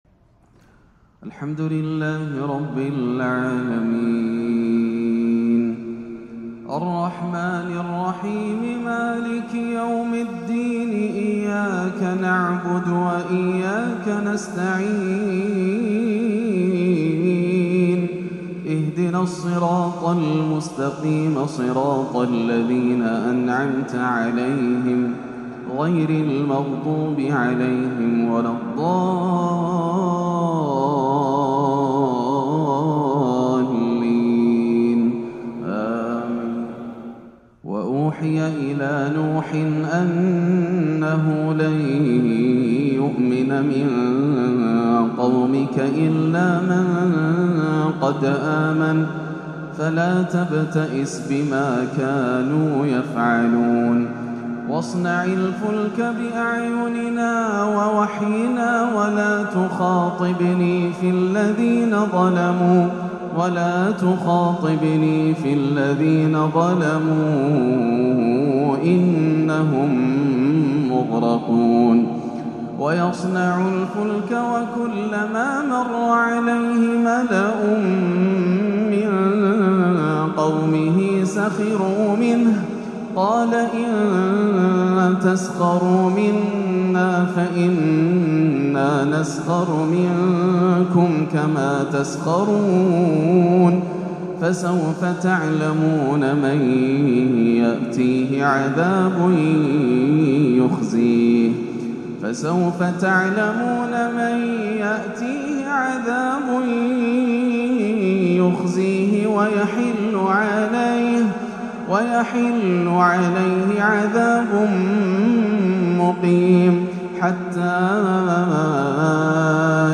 (ونادى نوح ابنه) آيات أبكت شيخنا الفاضل والمصلين بشدة - أجمل عشائيات العام - الإثنين 5-8-1438 > عام 1438 > الفروض - تلاوات ياسر الدوسري